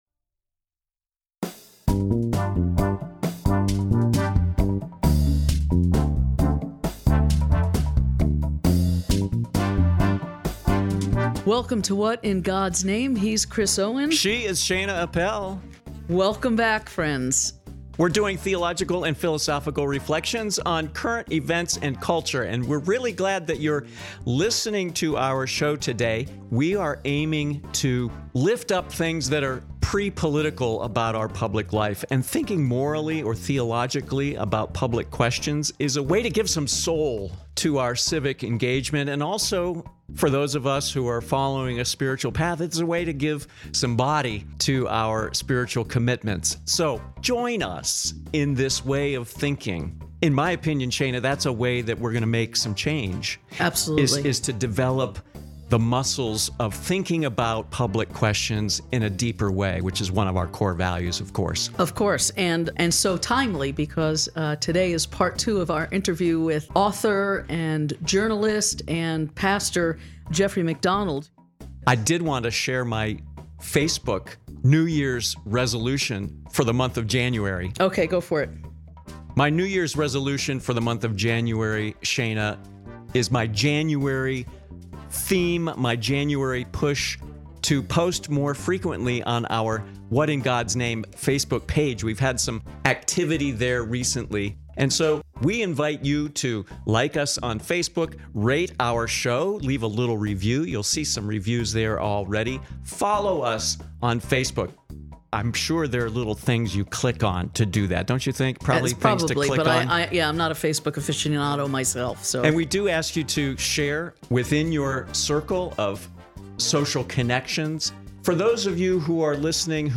Part Two of our conversation focuses on the ways our culture reduces human beings to consumers, and what we can do about it.